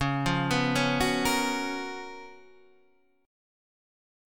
Db13 chord